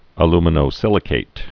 (ə-lmə-nō-sĭlĭ-kāt, -kĭt)